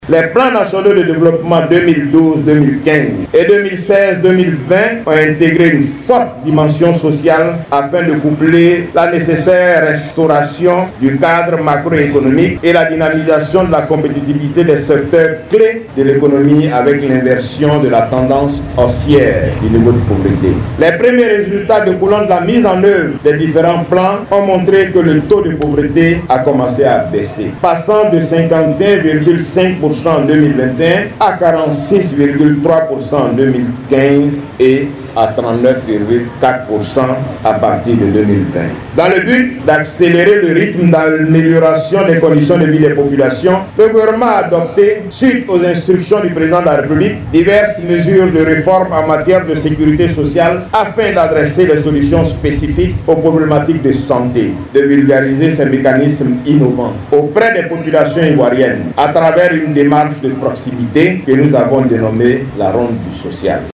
Le lancement de la troisième édition de la caravane “Ronde du social” à Bouaké le jeudi 28 juillet dernier, en vue de sensibiliser les populations à adhérer aux différents régimes sociaux, a été un prétexte pour le ministre de l’emploi et de la protection sociale, pour se prononcer sur la réduction du taux de pauvreté en Côte d’Ivoire.